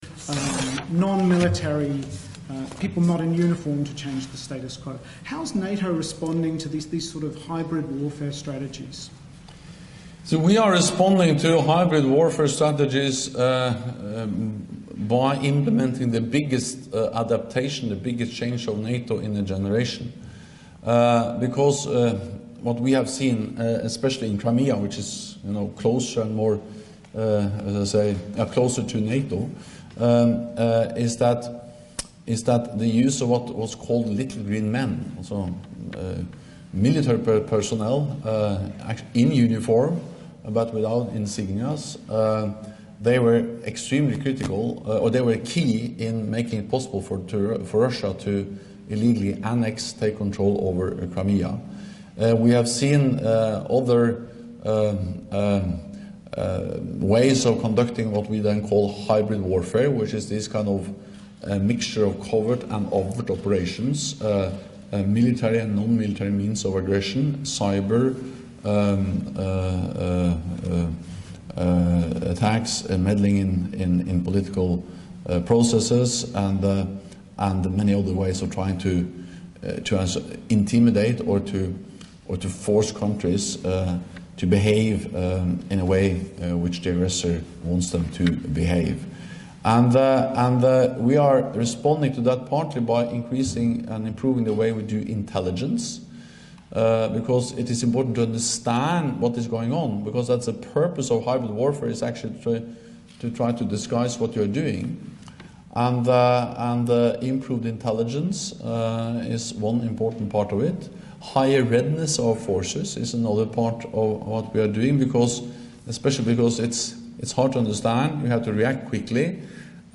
Speech
by NATO Secretary General Jens Stoltenberg at the Centre for Strategic Studies at Victoria University of Wellington in New Zealand